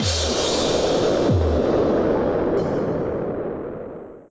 splash.wav